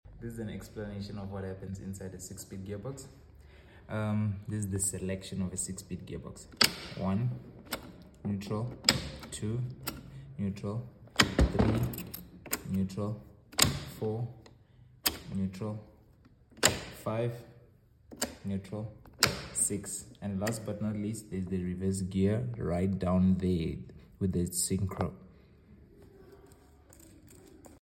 6 SPEED GEARBOX SELECTION⚙⚙⚙⚙⚙